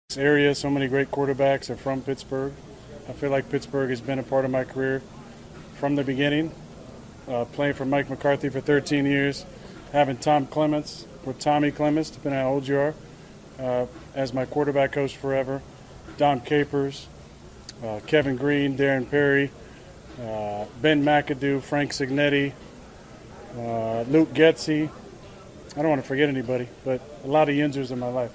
Rodgers says the Steelers are a great fit for him, mentioning a number of western PA football connections he’s made through the years, including two very familiar names in Indiana County.